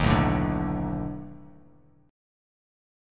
B7#9 chord